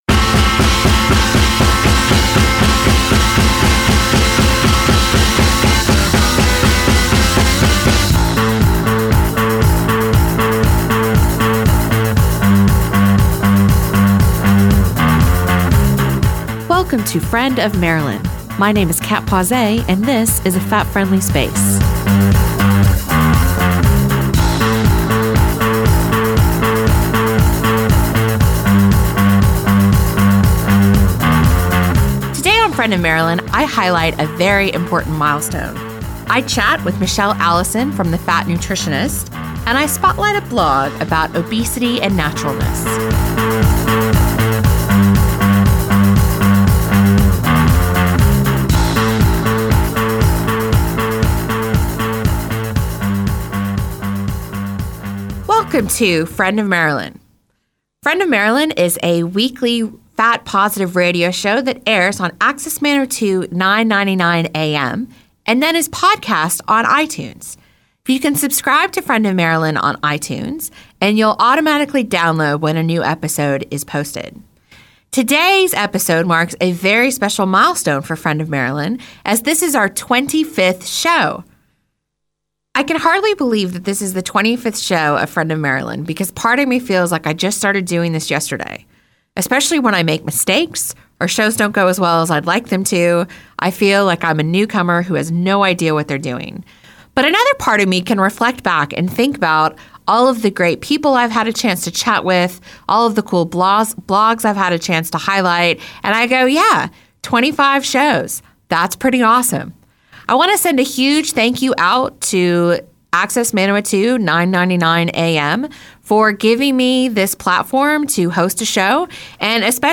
access radio